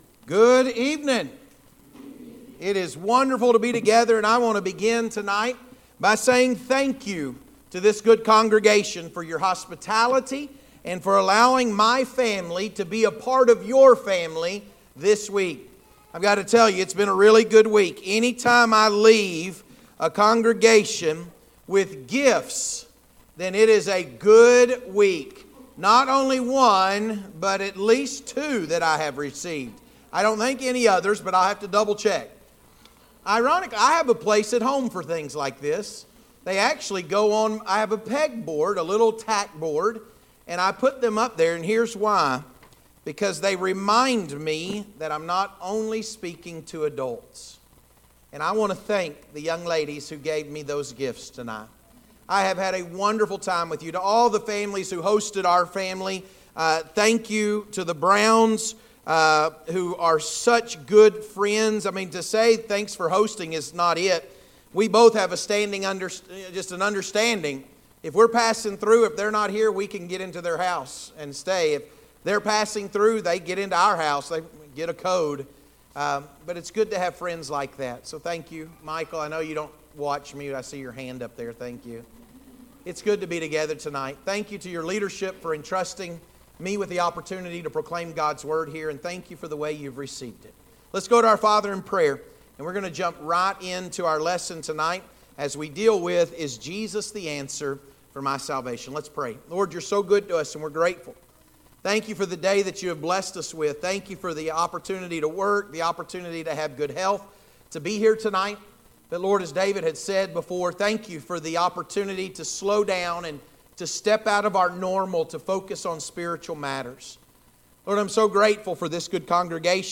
Is Jesus the Answer? Gospel Meeting Series